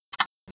A320_flaplever.wav